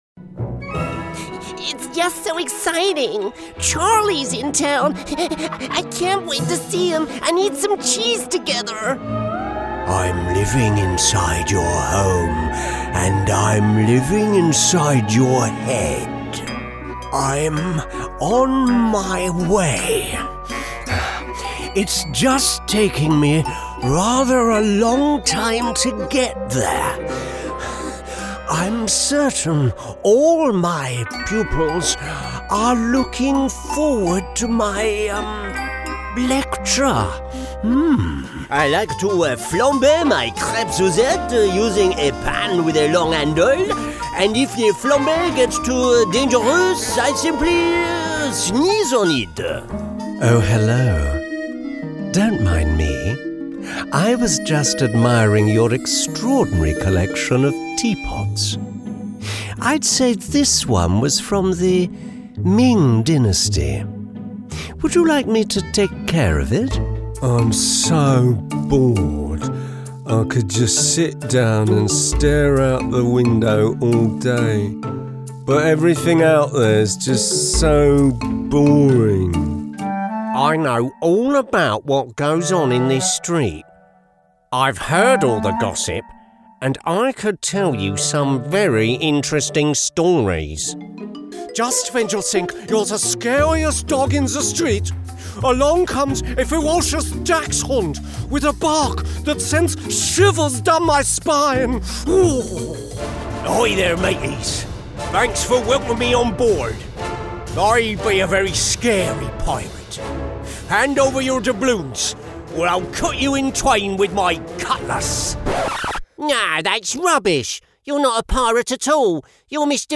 Playing age: 30 - 40s, 40 - 50s, 50 - 60s, 60+Native Accent: RPOther Accents: American, Estuary, Irish, London, Neutral, Northern, RP, Scottish, West Country, Yorkshire
• Native Accent: RP
• Home Studio